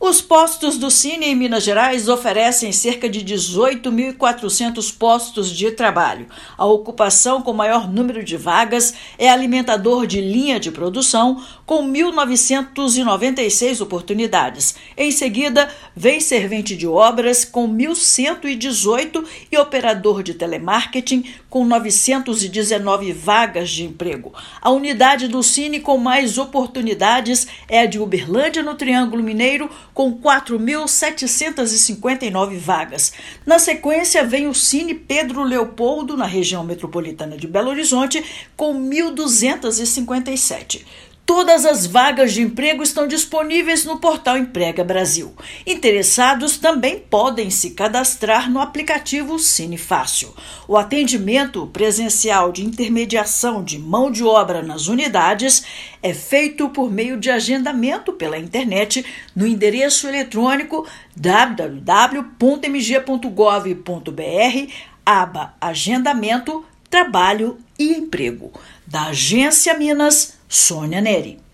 Página na internet permite que interessados em ingressar no mercado de trabalho busquem oportunidades nas unidades do estado. Ouça matéria de rádio.